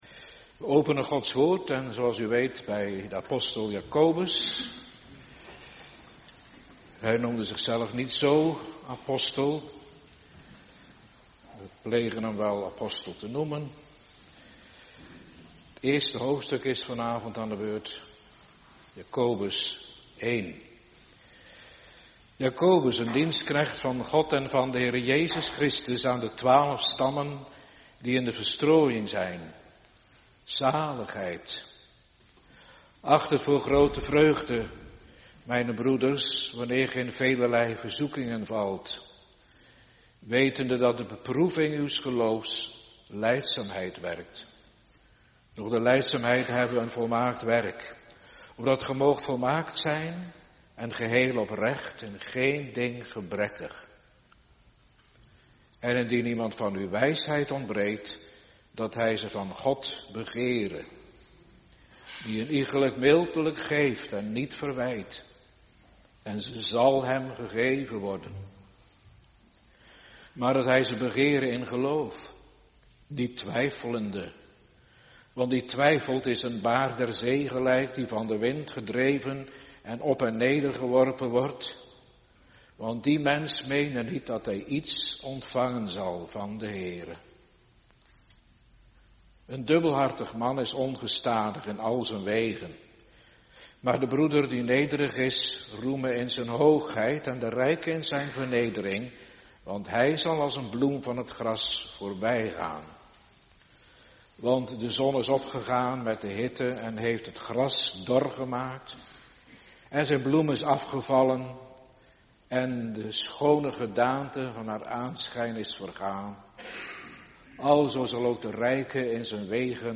De brief van de apostel Jakobus winteravondlezing (1)